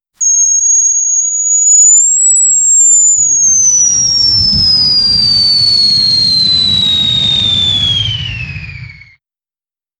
A realistic jet-engine spool-down SFX from a crompressor stall: start with a high-pitched turbine whine that smoothly drops in pitch over 3–5 seconds, includes slight mechanical rattles and airflow whoosh. 0:10 Created Jun 3, 2025 2:11 PM
a-realistic-jet-engine-sp-og5wfydh.wav